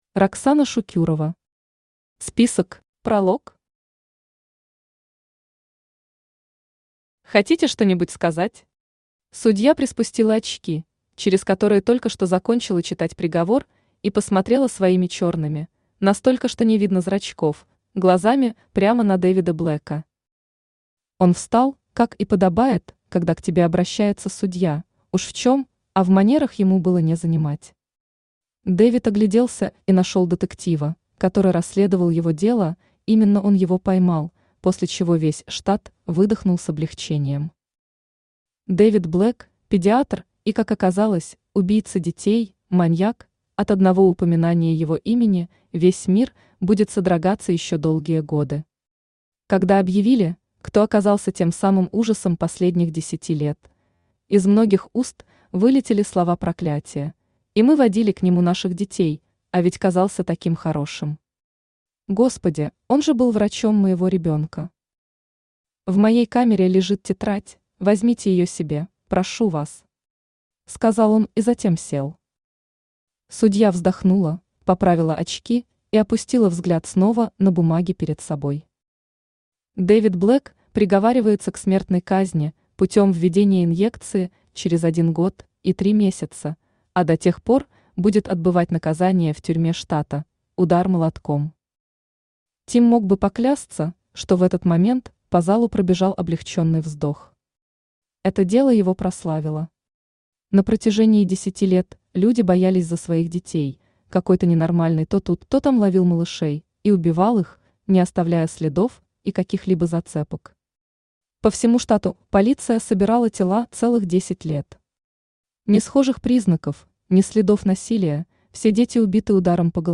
Аудиокнига Список | Библиотека аудиокниг
Aудиокнига Список Автор Роксана Шукюрова Читает аудиокнигу Авточтец ЛитРес.